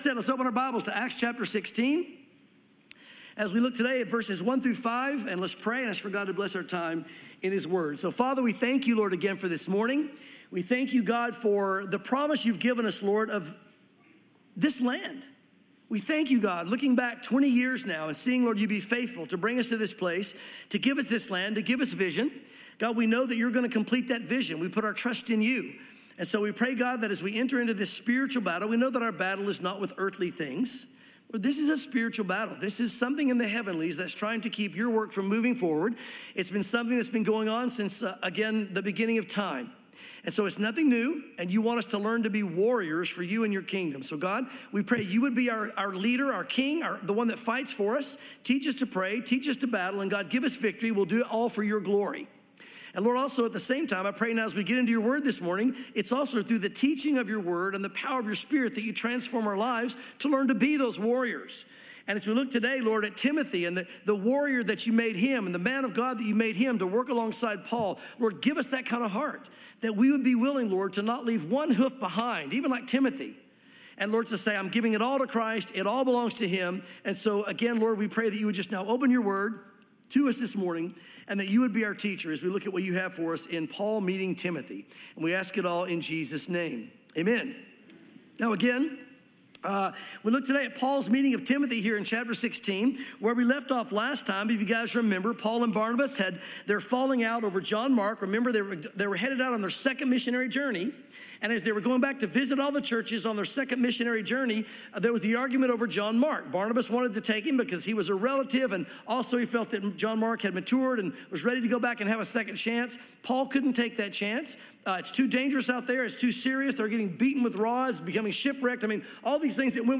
sermons Acts 16:1-5 | Paul Meets Timothy